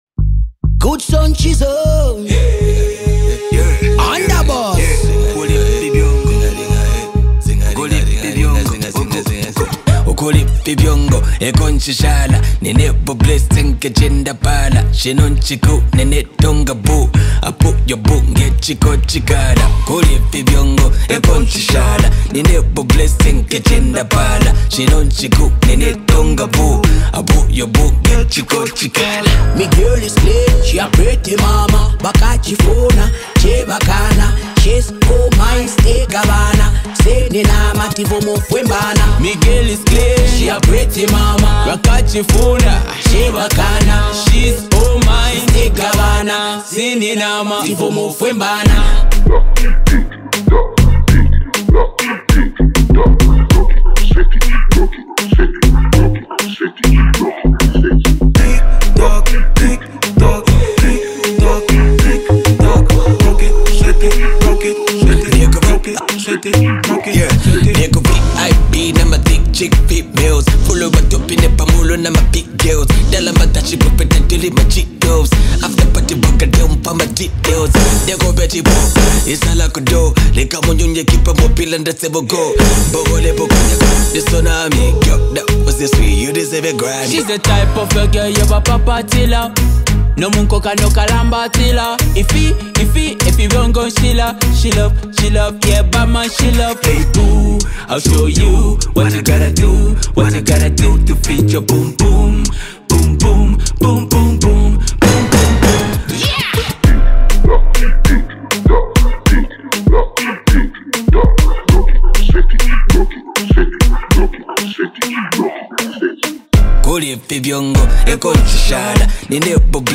🎶 Genre: Dancehall